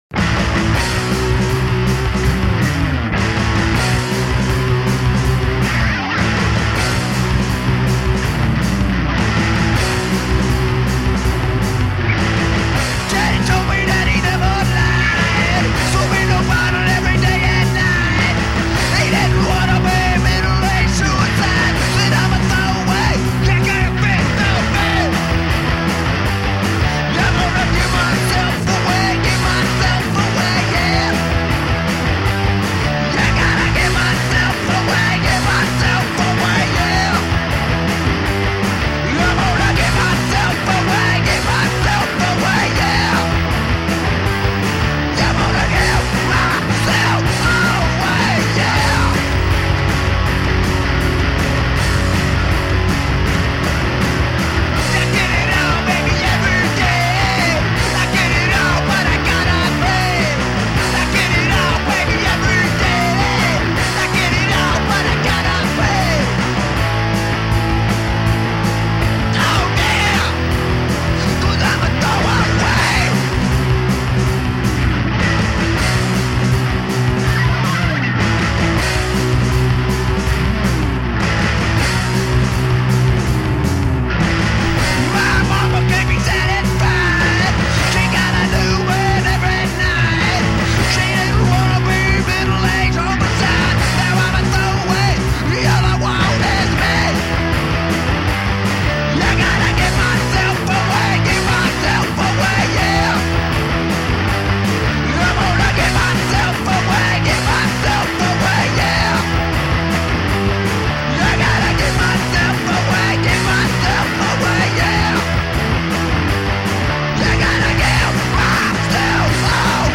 High energy rock and roll.
Tagged as: Hard Rock, Metal, Punk, High Energy Rock and Roll